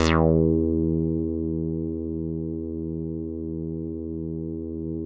HARD CUTOFF2.wav